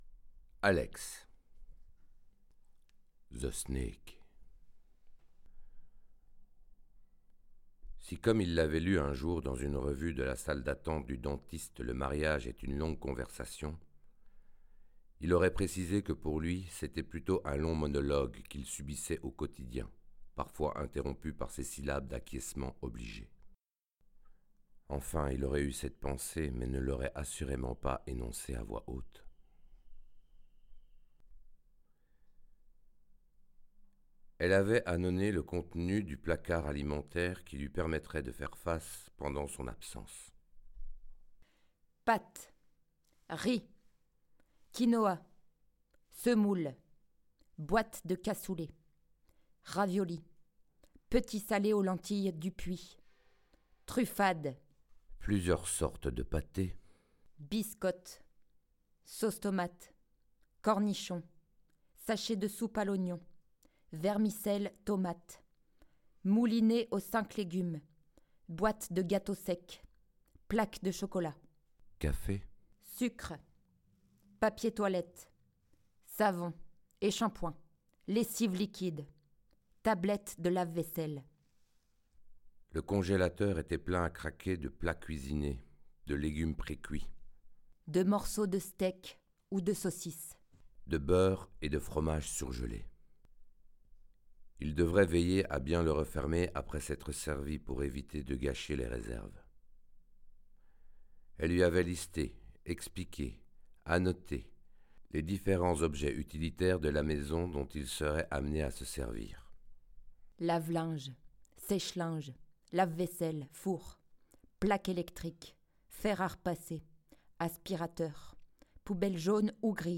Ecoutez la nouvelle « Alex the snake »